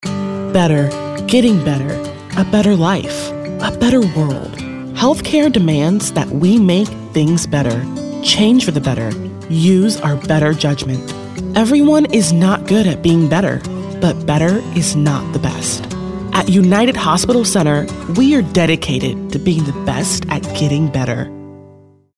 African American, anti-announcer, caring, compelling, confessional, conversational, friendly, genuine, girl-next-door, homespun, informative, inspirational, mellow, millennial, motivational, narrative, nostalgic, real, sincere, smooth, storyteller, thoughtful, warm